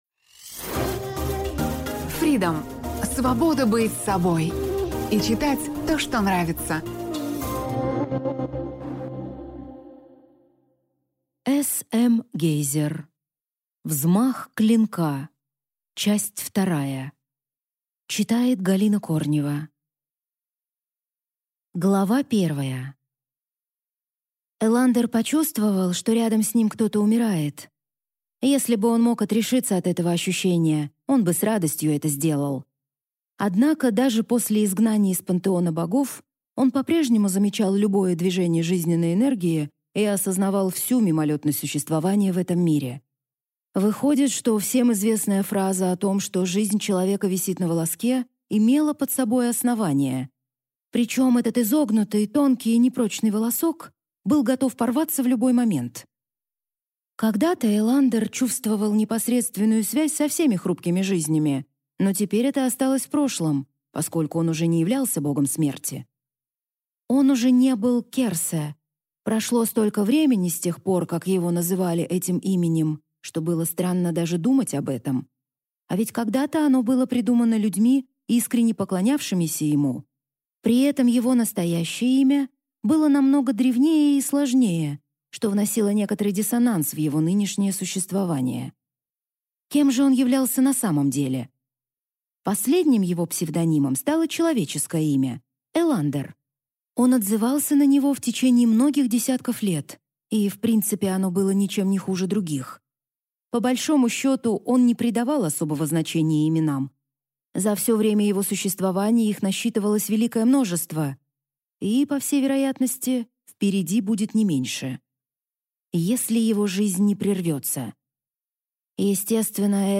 Аудиокнига Взмах клинка | Библиотека аудиокниг
Прослушать и бесплатно скачать фрагмент аудиокниги